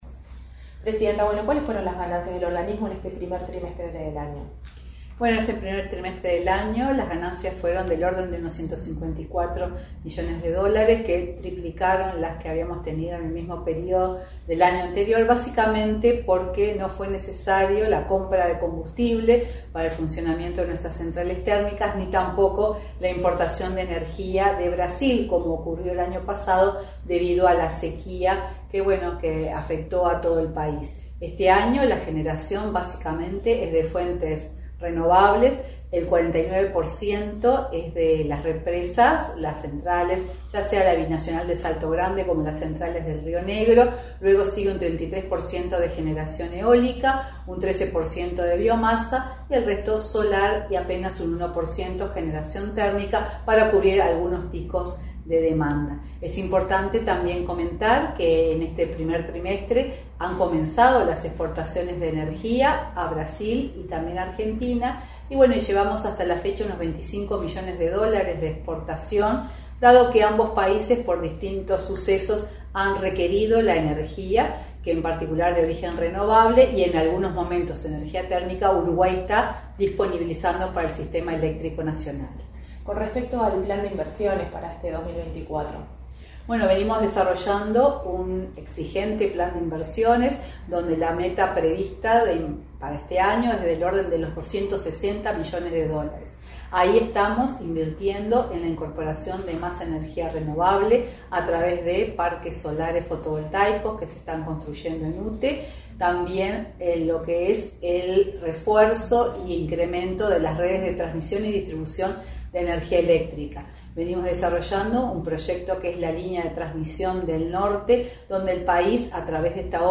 Entrevista a la presidenta de UTE, Silvia Emaldi